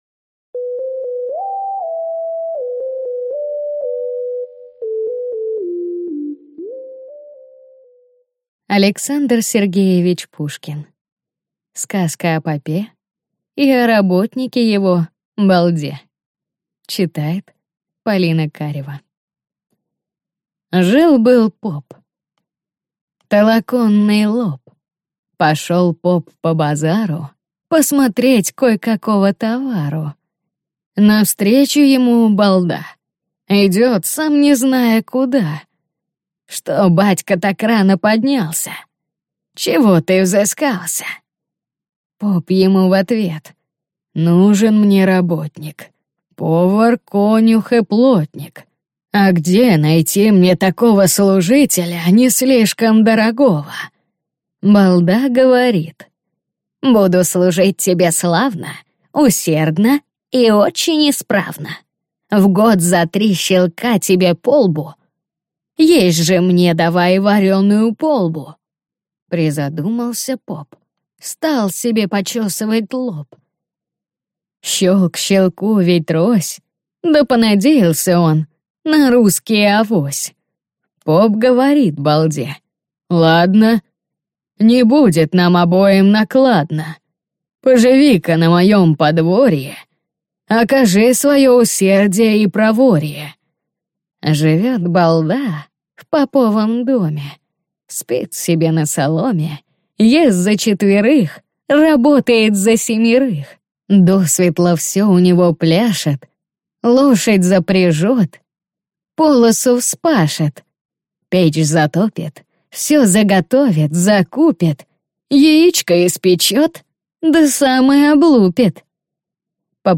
Аудиокнига Сказка о попе и о работнике его Балде | Библиотека аудиокниг